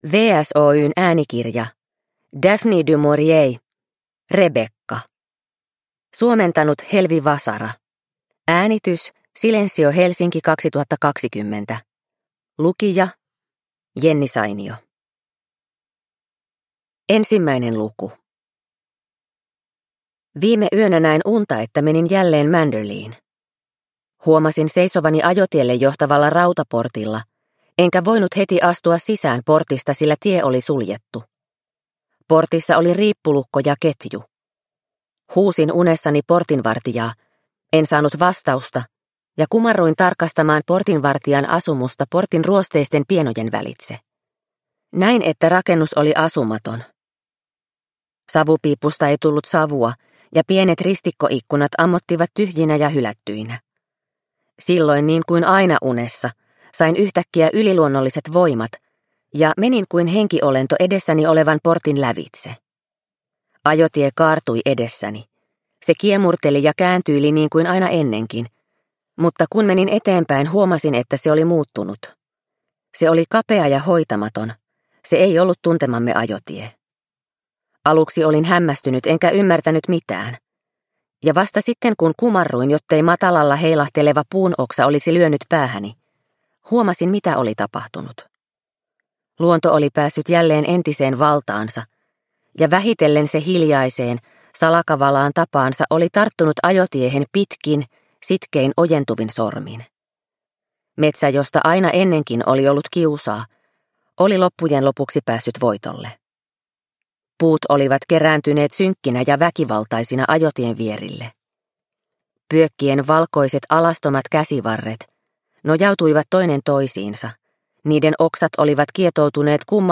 Rebekka – Ljudbok – Laddas ner